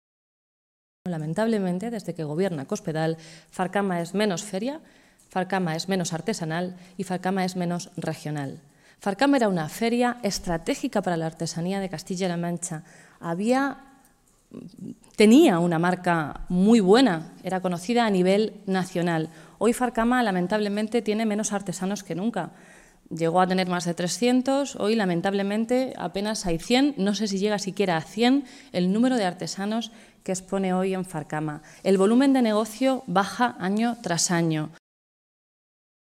Fernández se pronunciaba de esta manera esta mañana, en Toledo, en una comparecencia ante los medios de comunicación un día antes de la apertura oficial del curso universitario que este año tendrá lugar en la capital regional con la presencia de los Reyes de España.